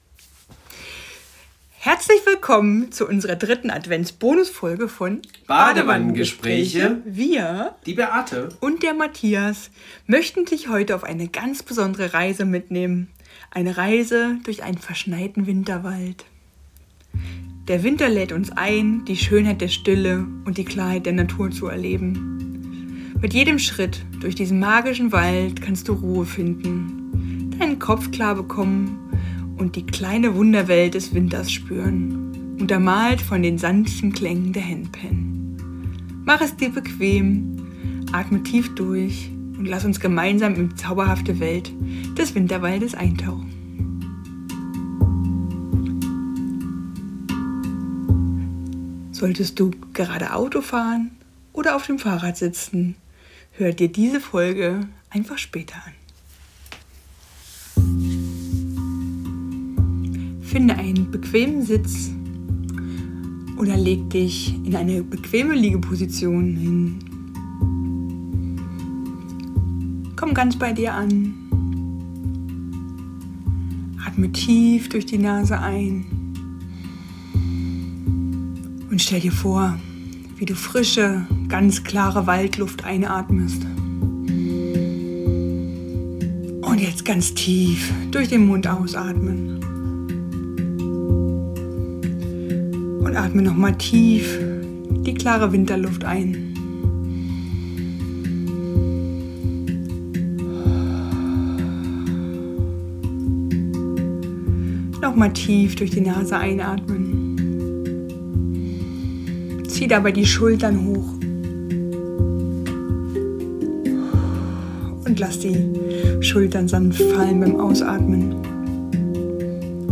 Handpan-Klangreise ~ Badewannengespräche Podcast